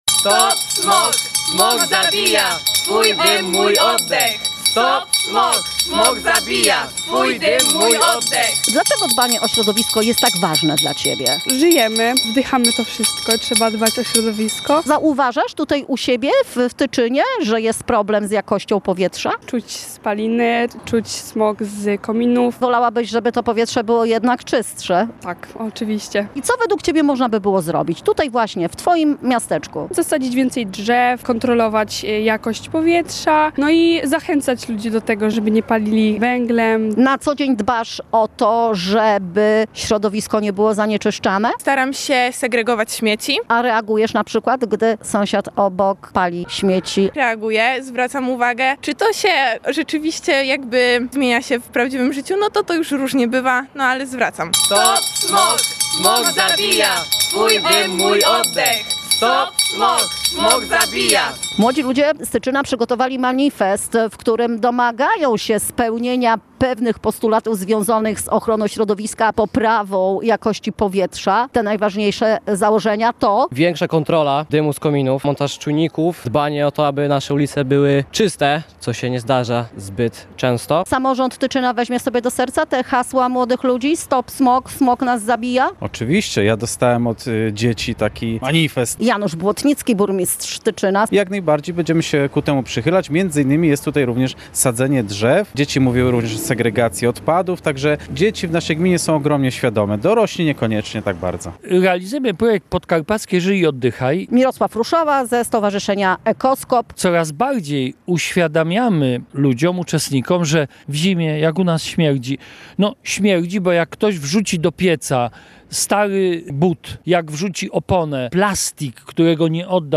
Happening na rynku w Tyczynie